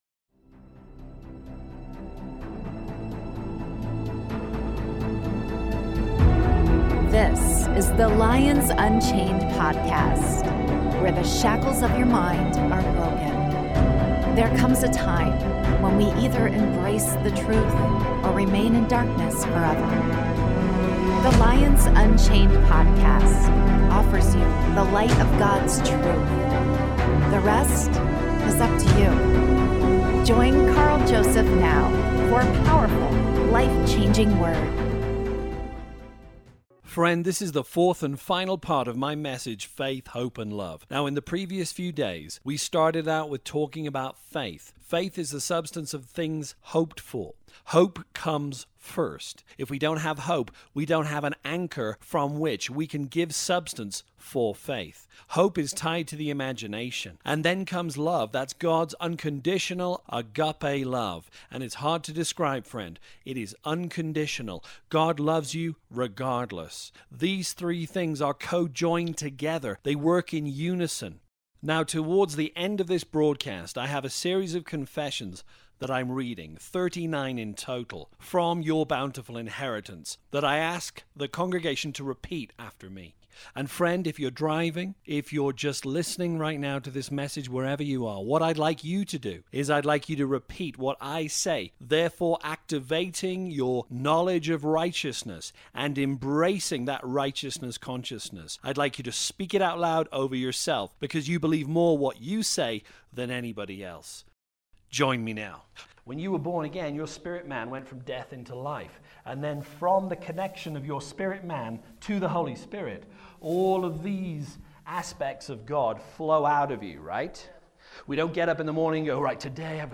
Part 4 (LIVE)